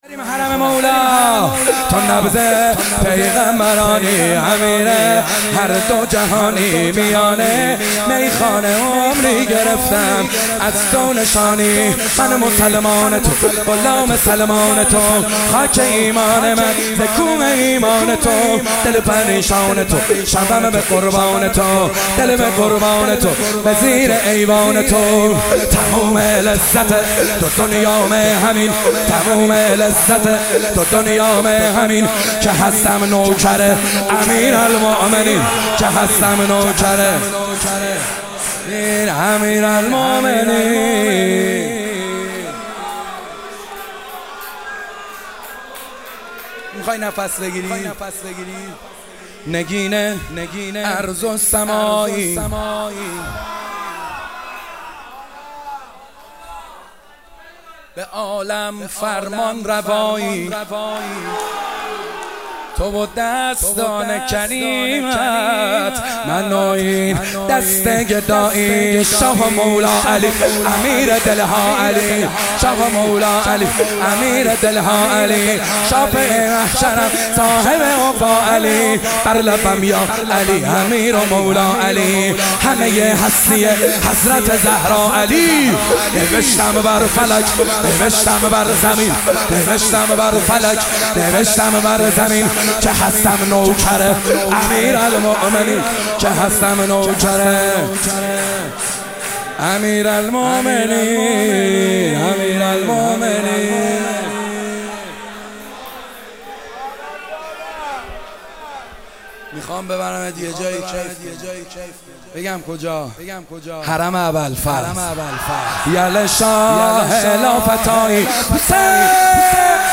کربلایی محمدحسین حدادیان
ولادت حضرت محمد(ص)امام جعفرصادق(ع)97 - شور 2